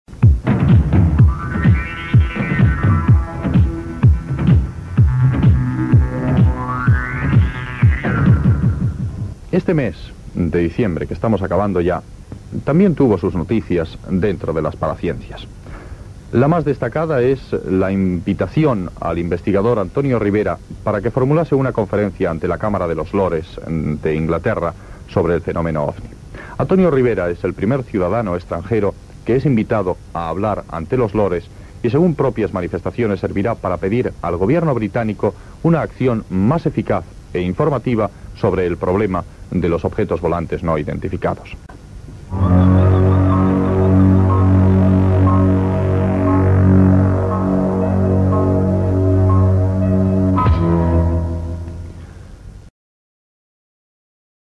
Breve comunicado de radio donde se cita la intervención de Ribera en la cámara de los lores británica.